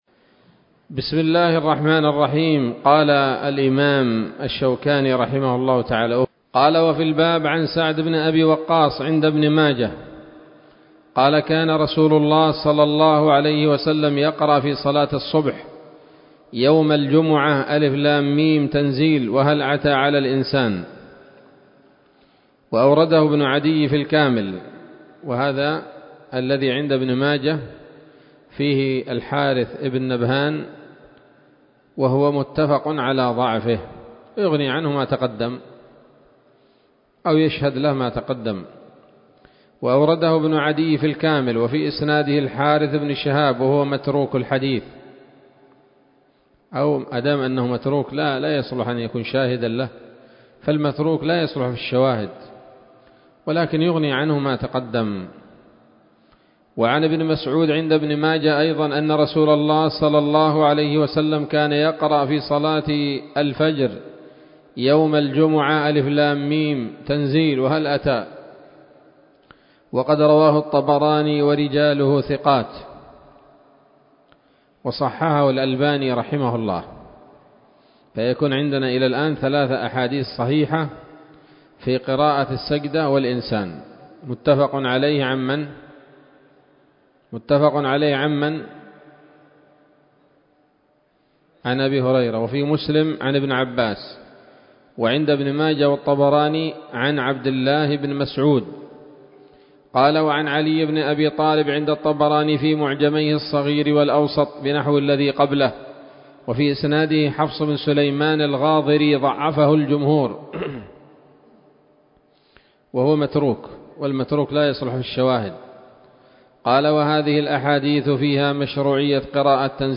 الدرس الثالث والثلاثون من ‌‌‌‌أَبْوَاب الجمعة من نيل الأوطار